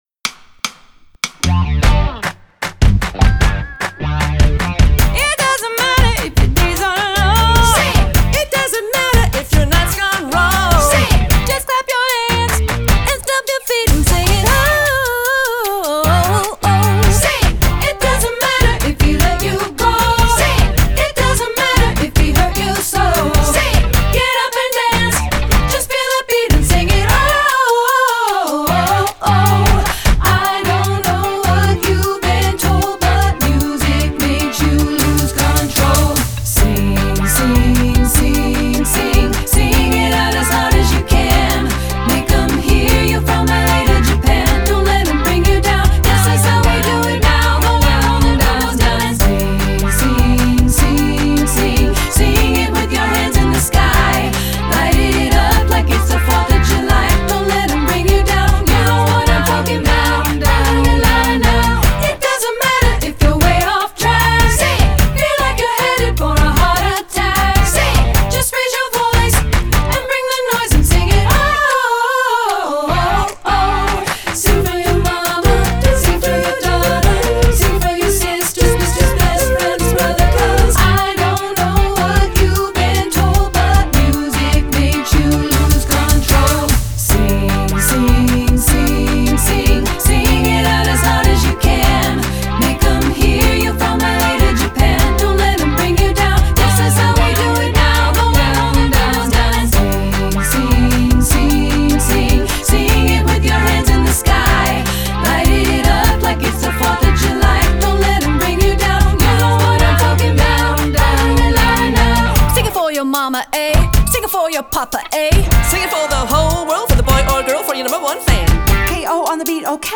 Choral Early 2000's Pop Women's Chorus
3pt Treble